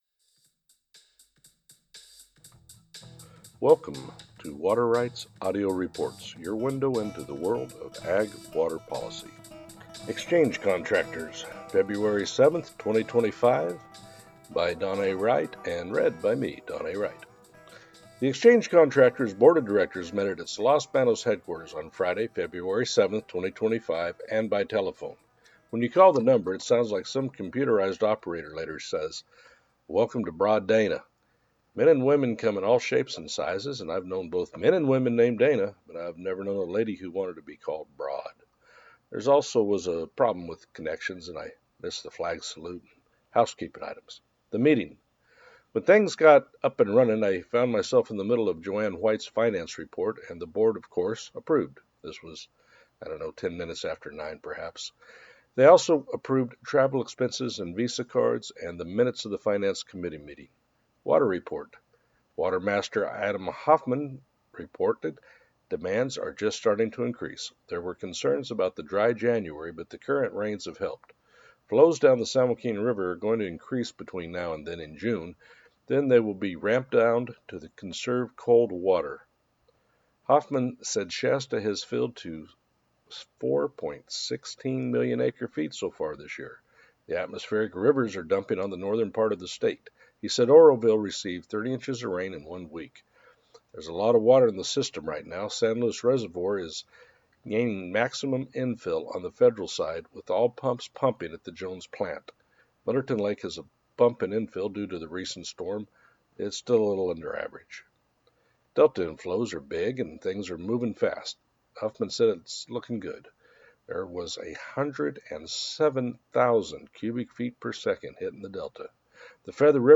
The Exchange Contractors board of directors met at its Los Banos headquarters on Friday, February 7, 2025 and by telephone. When you call the number, it sounds like some computerized operator lady says, “Welcome to Broad Dana.”
Also there was a problem with connections and I missed the flag salute and housekeeping items.